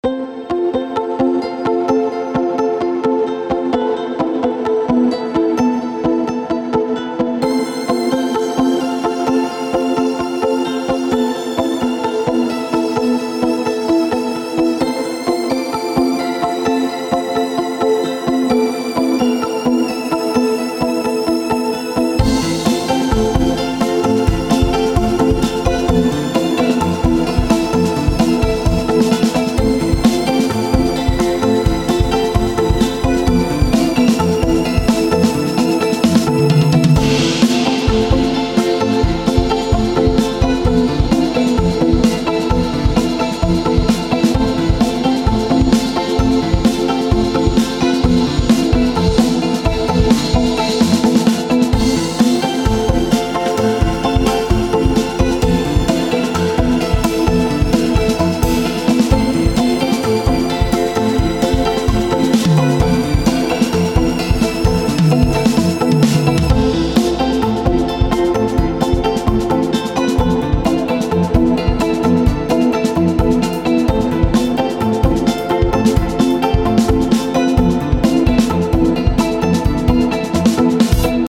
Buyrun bakalım dün akşam süppa ambians birşey karaladım :) Üzerinde daha uğraşmam lazım ama bu haliyle de dinleyiverin gari :) Nexus'a teşekkürlerimi sunarım ;)